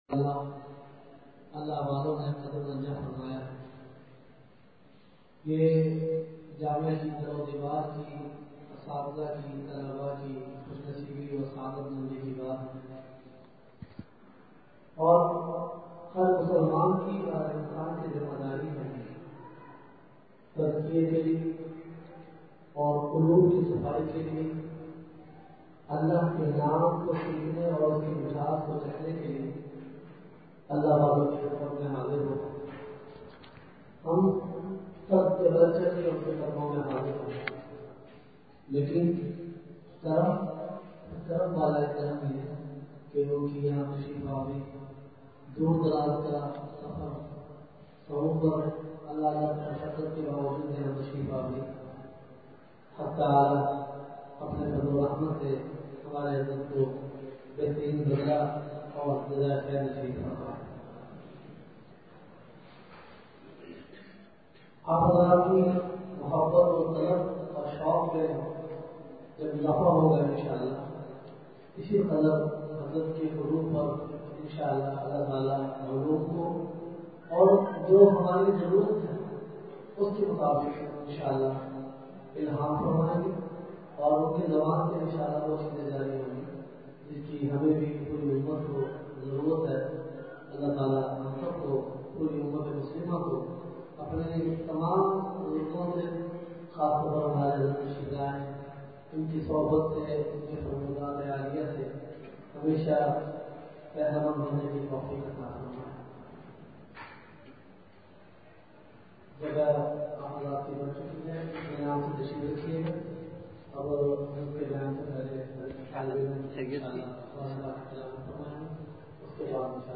*بمقام:۔جامعہ دارالقرآن مسلم ٹاون فیصل آباد*
*بعد مغرب* *نمبر(14):بیان*